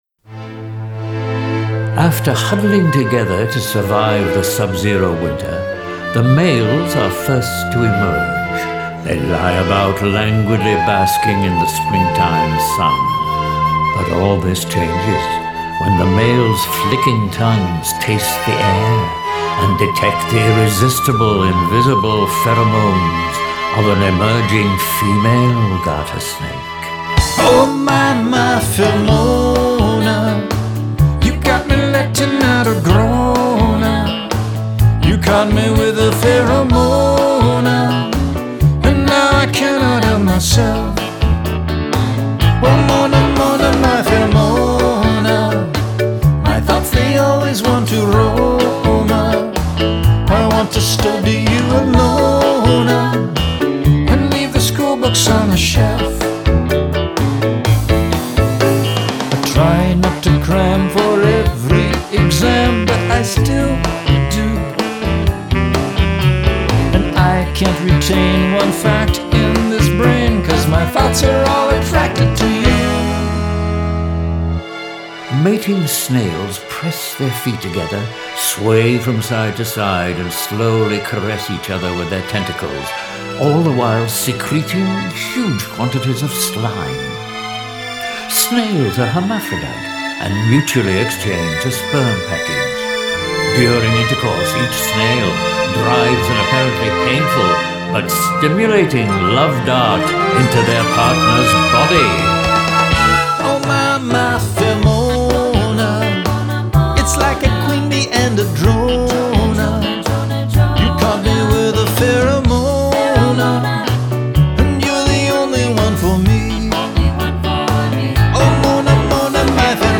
background vocals
guitar
flute
Sax
midi orchestra accompaniment is partly original and partly
This entry was posted in Eclectic Pop.